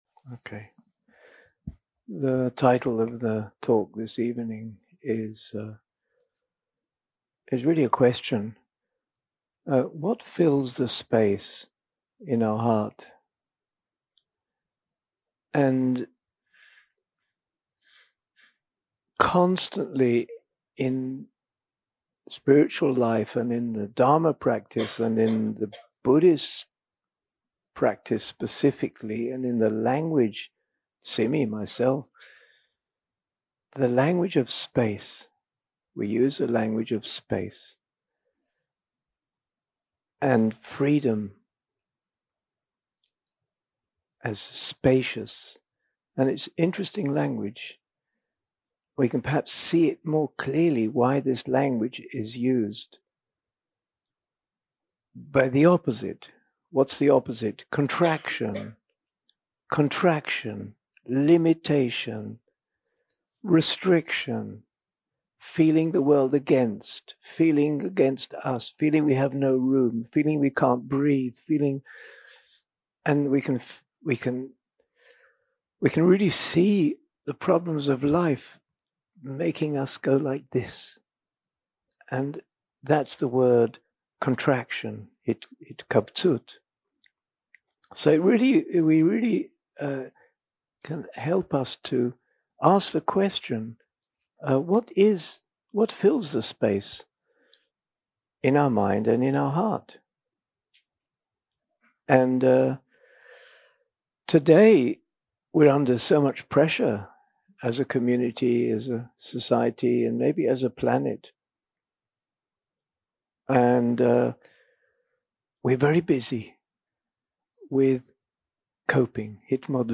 יום 2 – הקלטה 4 – ערב – שיחת דהארמה - What Fills the Space In Our Heart
יום 2 – הקלטה 4 – ערב – שיחת דהארמה - What Fills the Space In Our Heart 0:00 0:00 סוג ההקלטה: Dharma type: Dharma Talks שפת ההקלטה: Dharma talk language: English